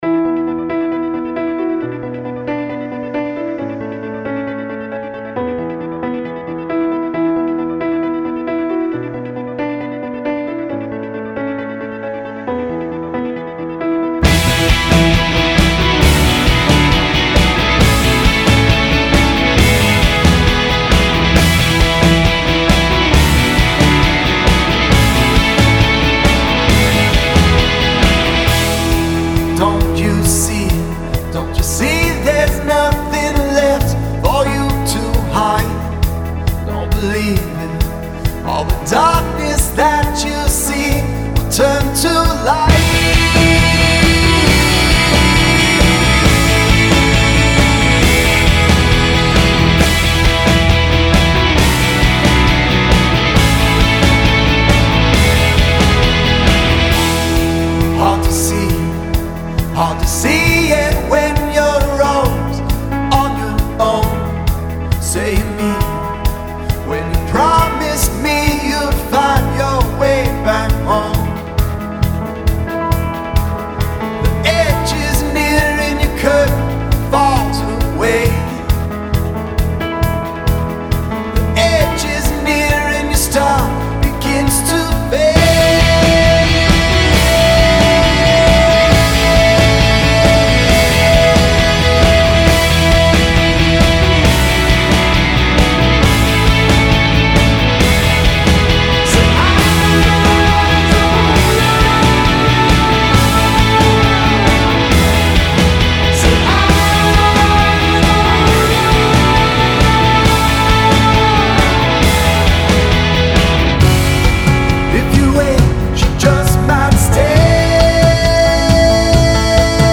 Full Vocals / Rock